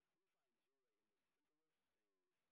sp07_street_snr20.wav